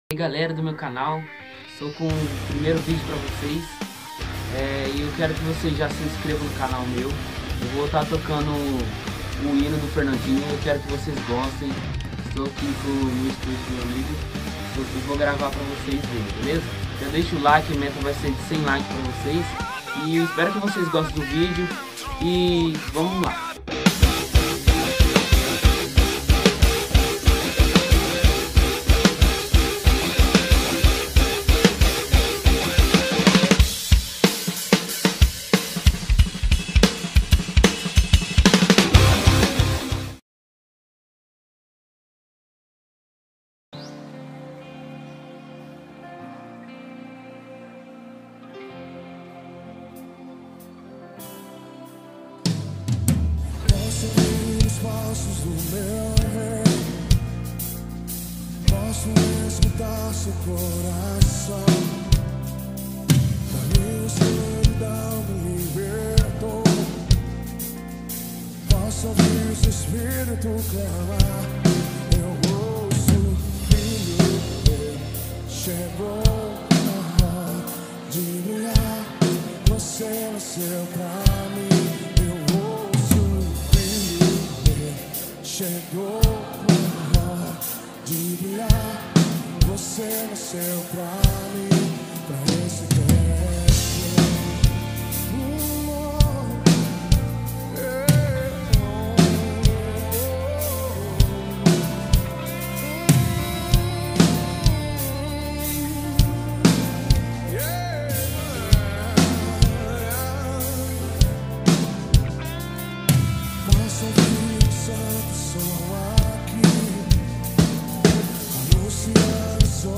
Drum cover